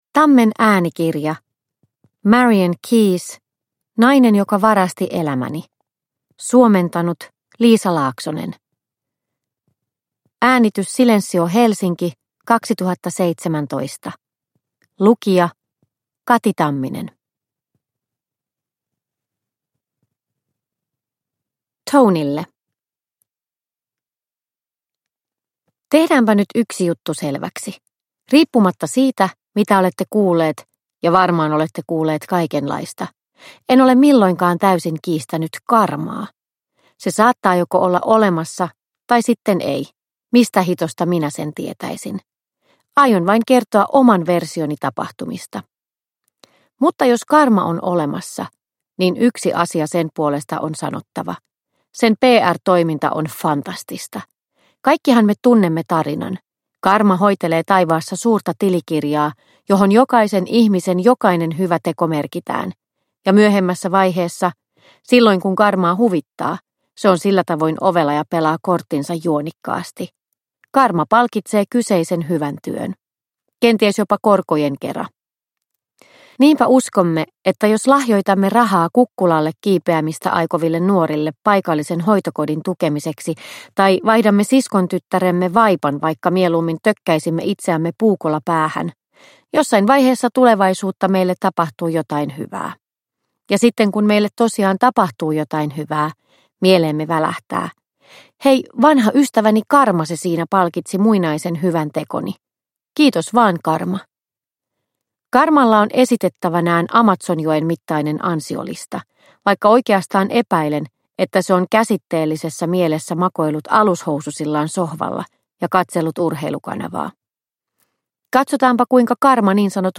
Nainen joka varasti elämäni – Ljudbok – Laddas ner